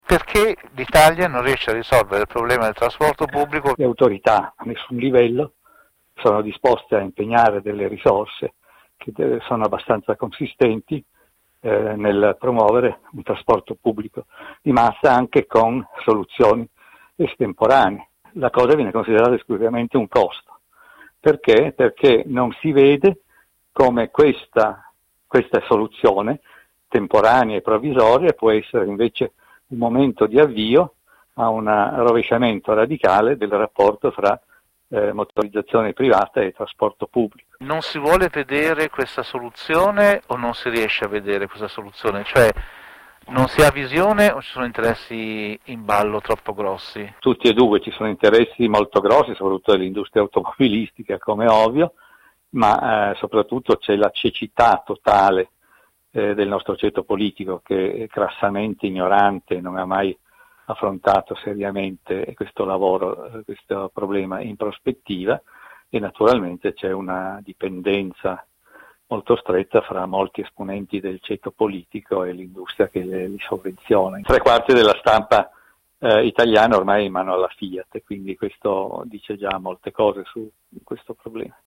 Il racconto della giornata di martedì 5 gennaio 2021 attraverso le notizie principali del giornale radio delle 19.30, dai dati dell’epidemia in Italia alla crisi del governo Conte che non sembra vicina ad una soluzione, mentre i problemi del trasporto pubblico in Italia in vista della riapertura delle scuole sono tutt’altro che risolti.